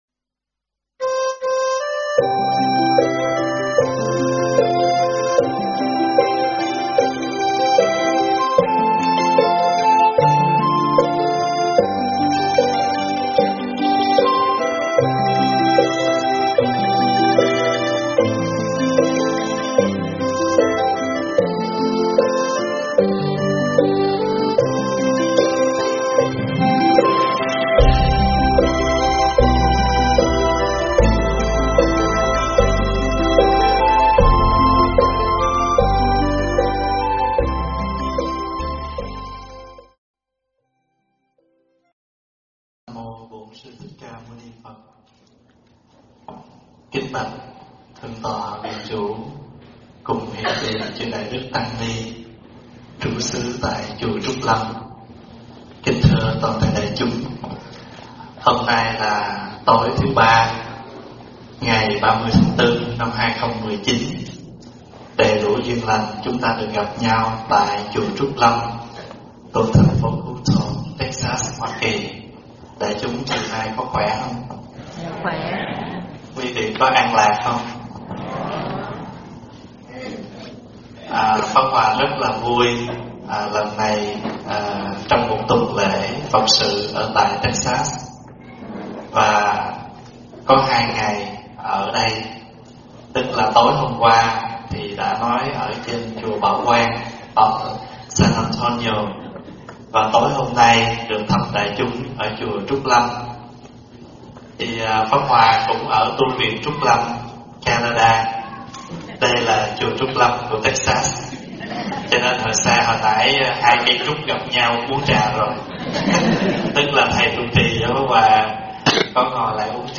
Mp3 thuyết pháp Sống Đời Tu Đạo
giảng tại Chùa Trúc Lâm, Hutto, TX